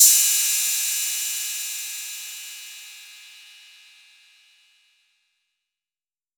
• Cymbal D Key 11.wav
Royality free cymbal sound tuned to the D note. Loudest frequency: 8706Hz
cymbal-d-key-11-CXf.wav